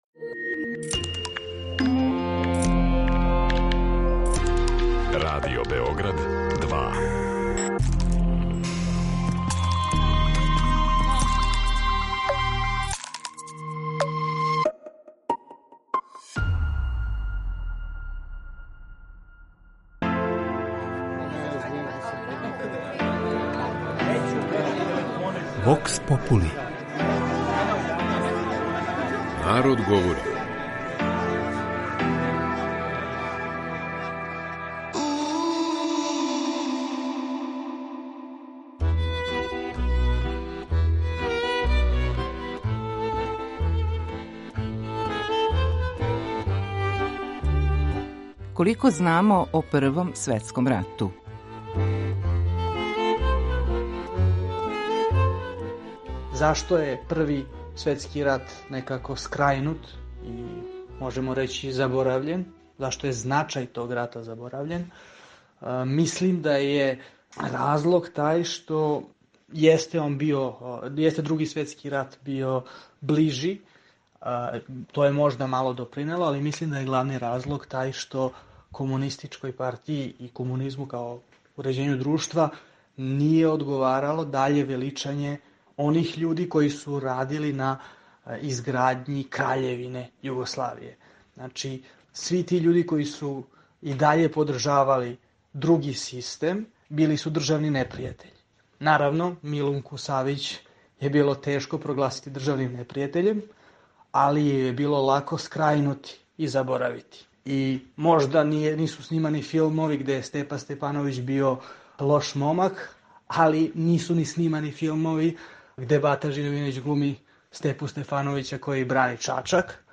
У сусрет 11. новембру, који се слави као Дан примирја у Првом светском рату, разговарали смо са нашим суграђанимa о томе колико је сећање на Велики рат данас присутно и колико заправо знамо о том значајном историјском периоду.
Вокс попули